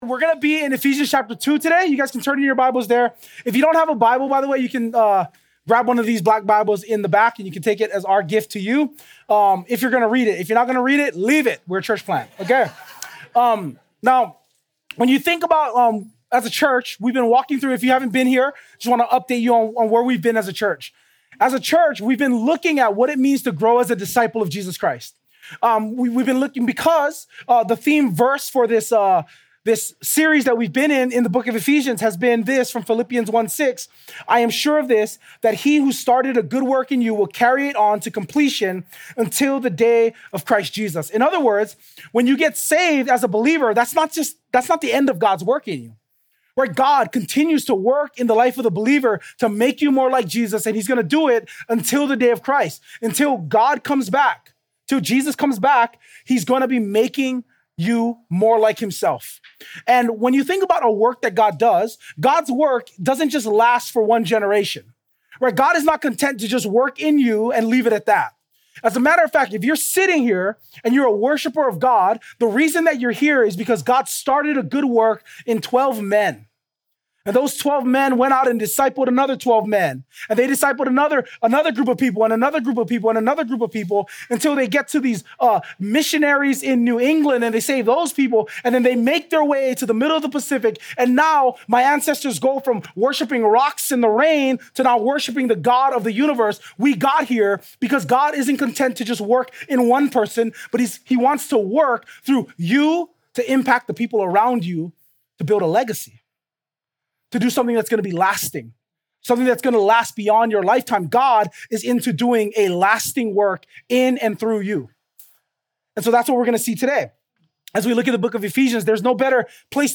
2025 Legacy-Builders Until The Day Preacher